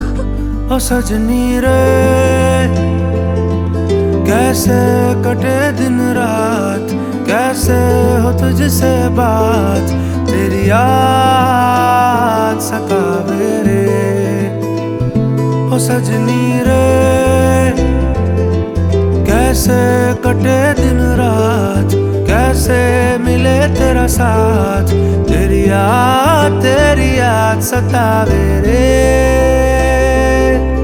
BGM Ringtone
Categories: Hindi Ringtones